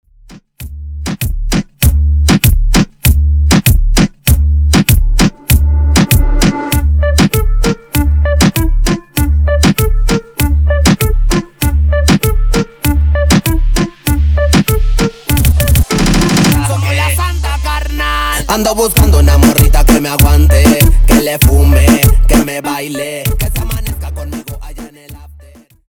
Extended Dirty Intro